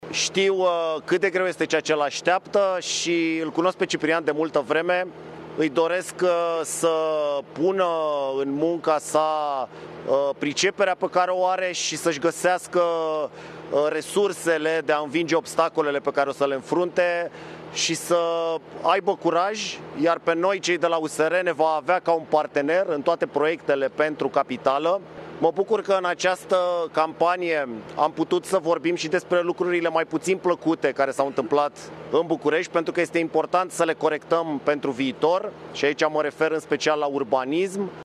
Candidatul USR – Cătălin Drulă – și-a recunoscut înfrîngerea în discursul pe care l-a avut imediat după închiderea urnelor.